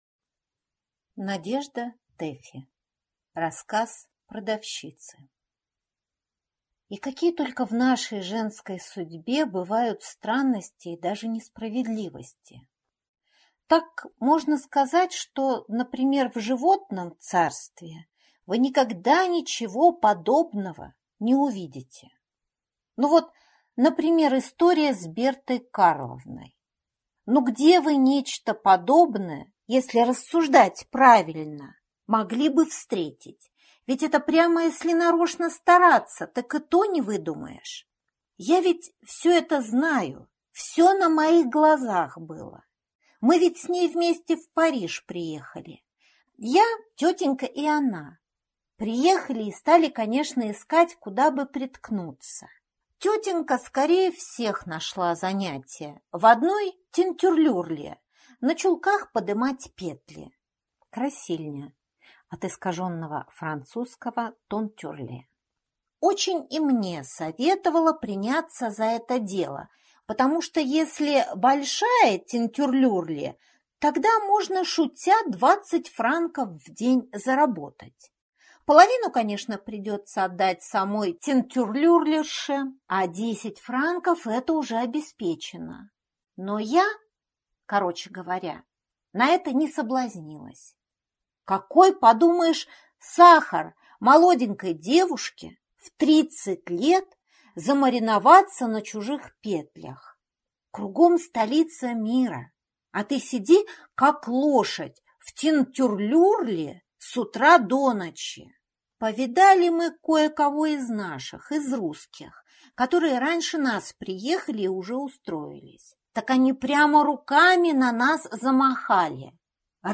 Аудиокнига Рассказ продавщицы | Библиотека аудиокниг